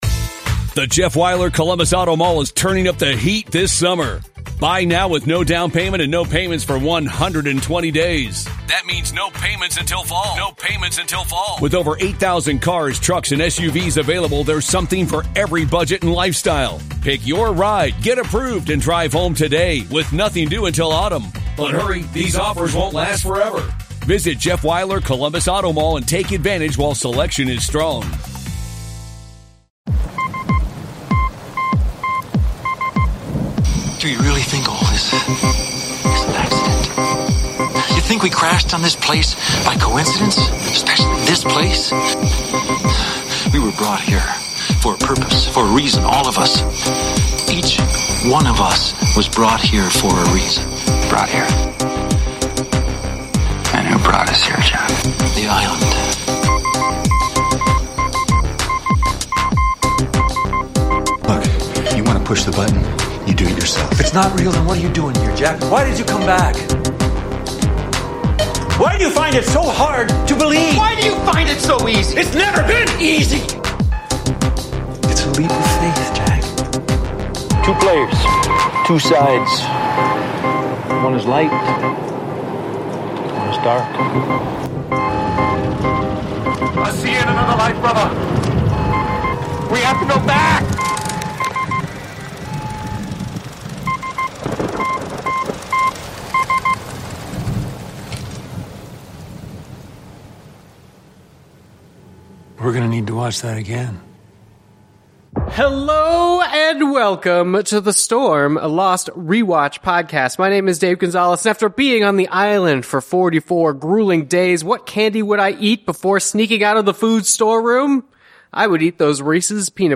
THE INTERVIEW (No Spoilers) - 45 minutes and 7 seconds THE STORM (SPOILERS!)